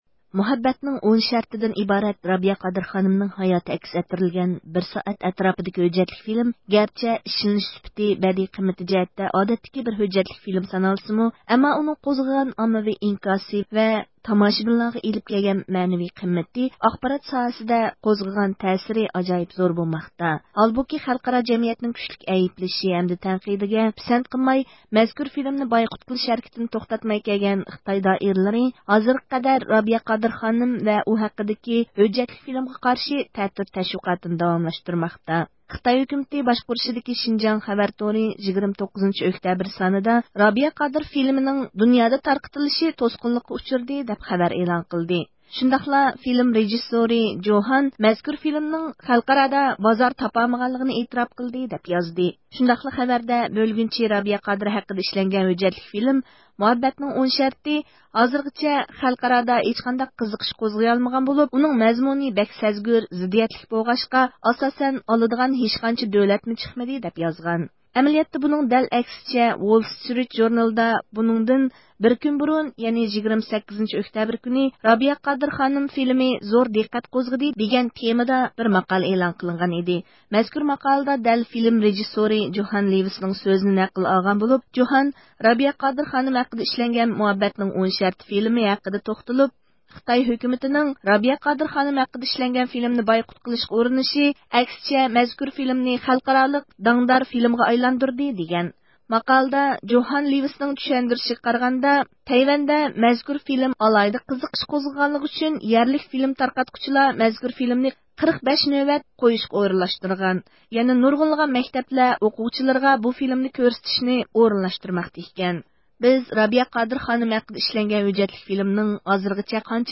بۇ مۇناسىۋەت بىلەن بىز رابىيە قادىر خانىمنىڭ ۋاشىنگىتوندىكى ئىشخانىسىغا تېلېفون قىلىپ «مۇھەببەتنىڭ 10 شەرتى» فىلىمىنىڭ ھازىرغىچە بولغان تارقىتىلىش، قارشى ئېلىنىش ئەھۋاللىرى ھەققىدە مەلۇمات ئالدۇق.